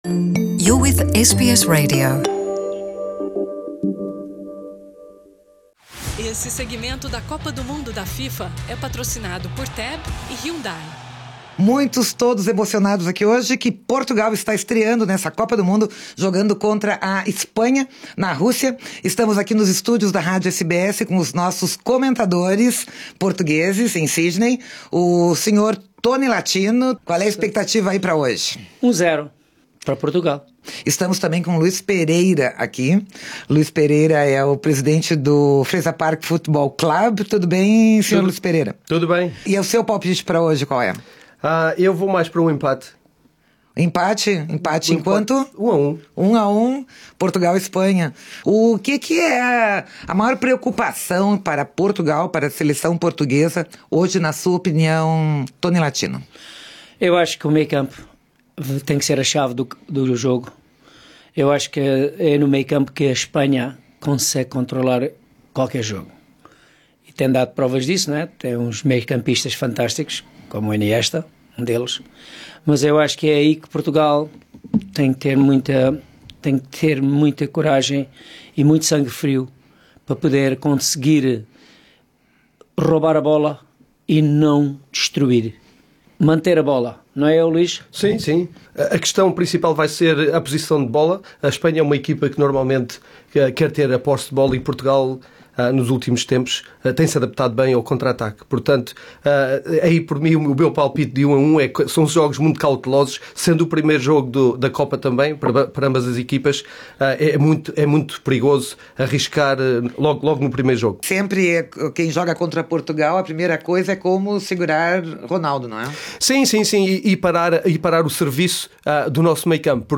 Ouça aqui a narração dos seis gols da partida e os melhores momentos da transmissão ao vivo do jogo pela SBS em Português, em parceria com a Antena 1/RTP. É a SBS em Português na Copa do Mundo da FIFA na Rússia.